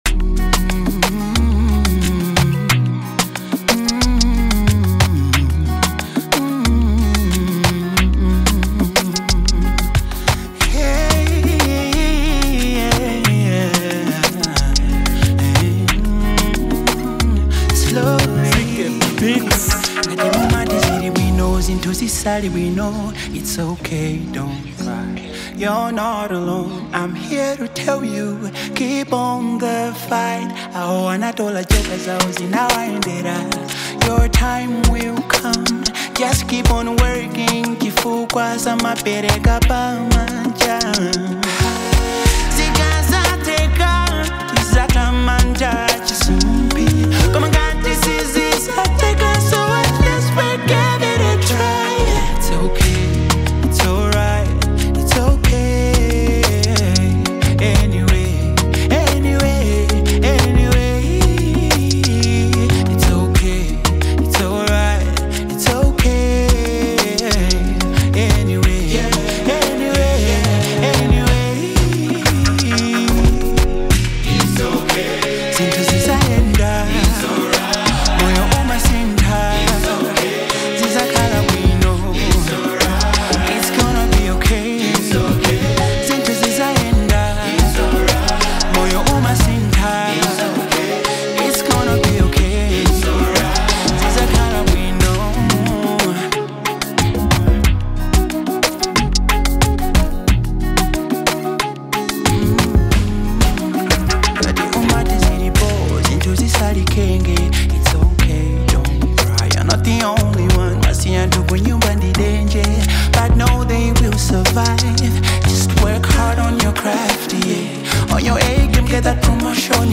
soul-stirring ballad